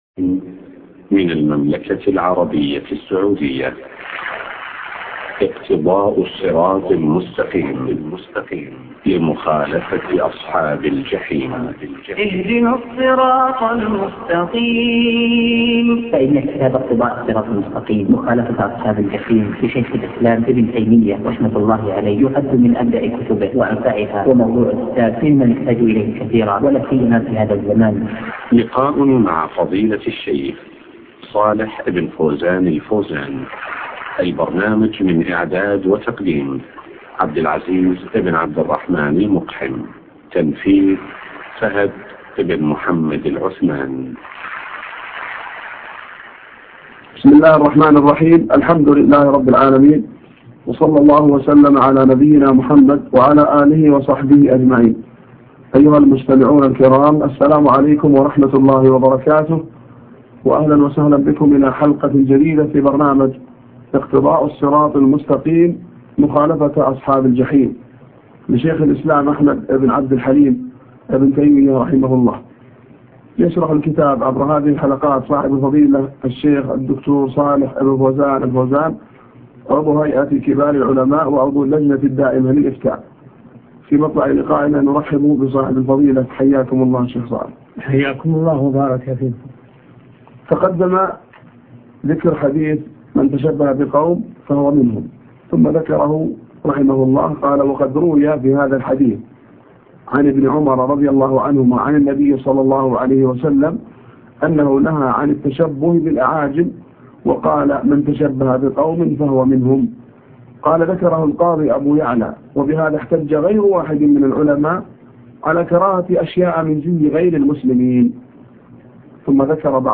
اقتضاء الصراط المستقيم شرح الشيخ صالح بن فوزان الفوزان الدرس 31